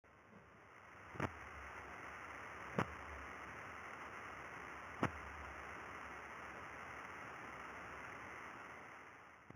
¶ Light Modulation Description: Light modulation glitches often look like several bright spikes in close succession with low frequency noise at the same time and after the bright spikes. This glitch predominantly occurred at LIGO Hanford during the first two observing runs (O1 & O2) due to amplitude fluctuations in the signal used to generate the 45 MHz optical sidebands (used to control LIGO's mirrors).